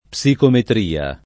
[ p S ikometr & a ]